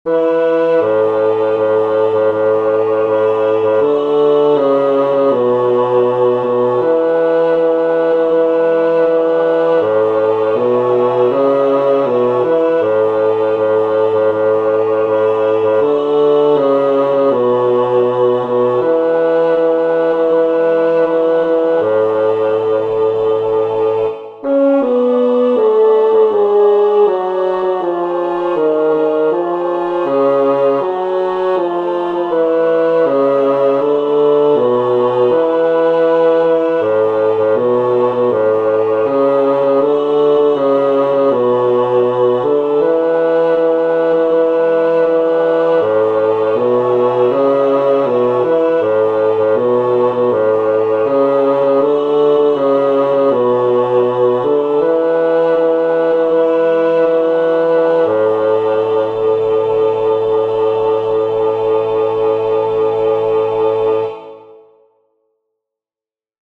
Esta canción popular tiene una estructura muy sencilla, tipo A-B-A’.
El tempo aparece indicado como Moderato.
o-sari-mares-bajo.mp3